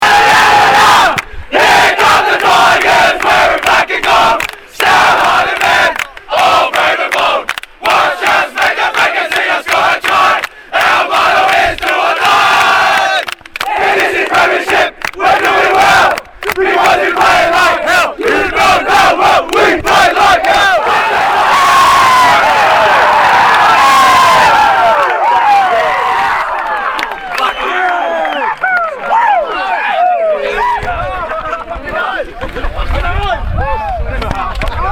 BALMAIN TIGERS SING THEIR VICTORY SONG AFTER THE GRAND FINAL
balmain_sgBall_sing_victory_song.mp3